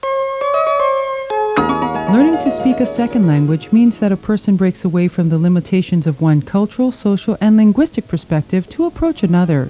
Samples of Public Service Announcements (PSA) are available in WAV format: